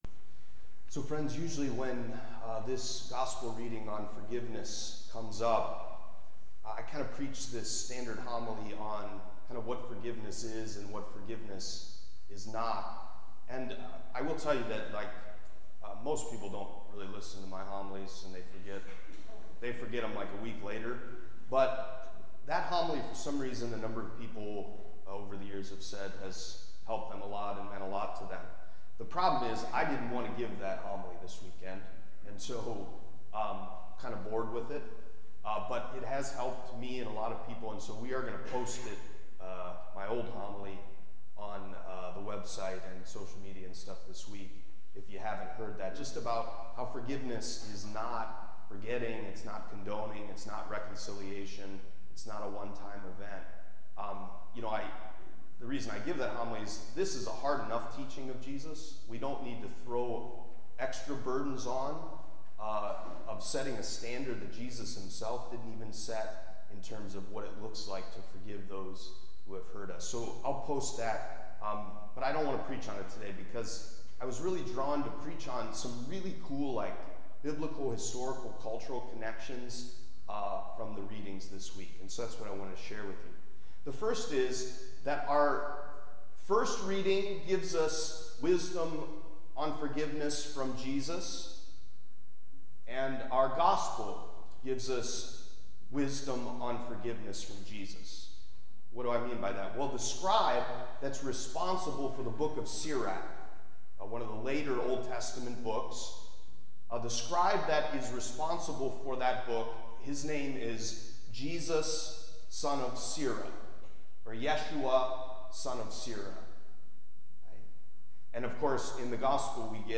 Homily from the 24th Sunday in Ordinary Time, Sunday, September 17, 2023